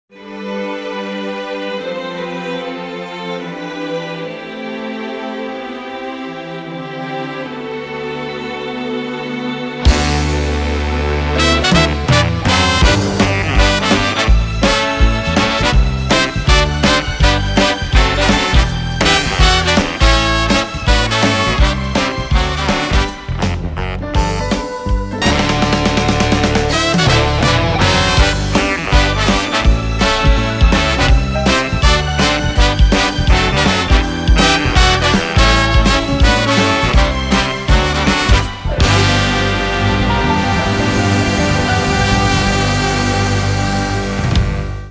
難易度 初〜中 分類 駈足164 時間 ３分48秒
編成内容 大太鼓、中太鼓、小太鼓、トリオ、シンバル 作成No 372